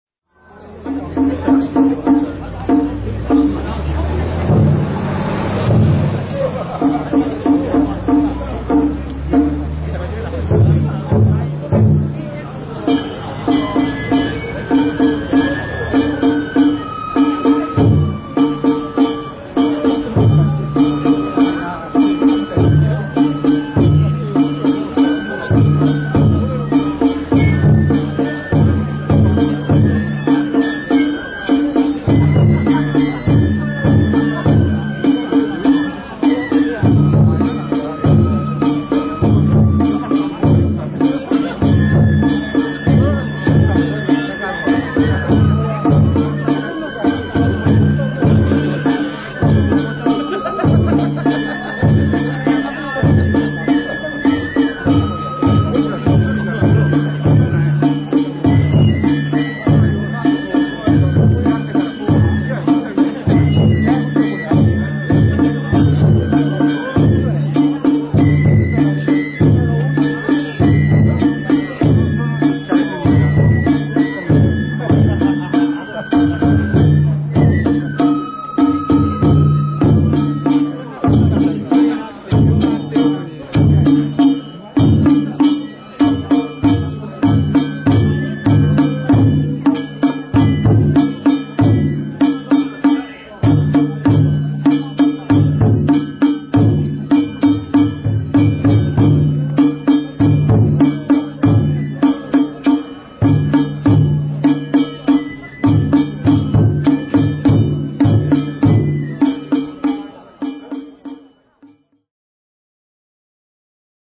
春分の日のこの日、三日市北部地車修復完成入魂式とお披露目曳行が行われました。
哀愁を帯びた笛の音を響かせたええ感じのお囃子です。